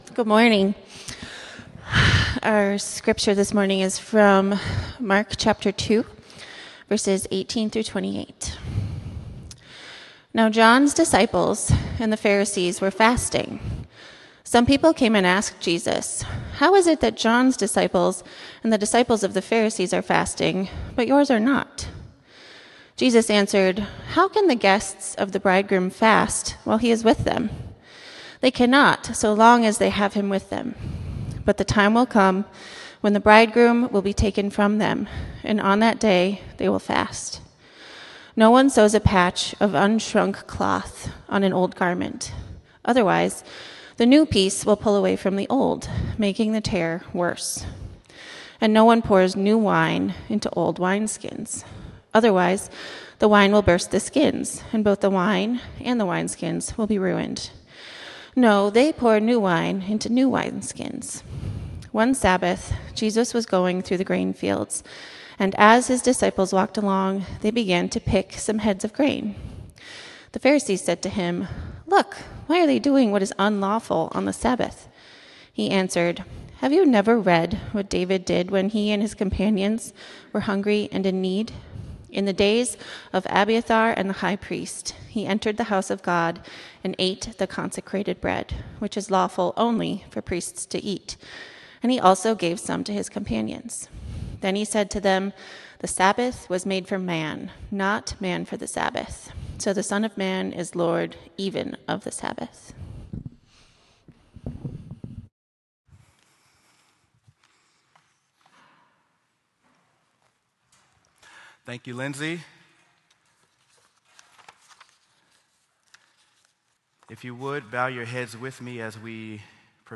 Sermon: Mark: Jesus Faces Opposition
sermon-mark-jesus-faces-opposition.m4a